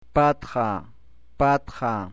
Plain Occlusives p